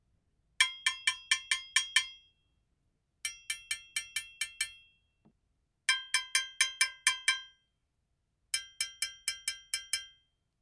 ギニア製 ドゥンドゥンベル
リング/スティック~裏リング/スティック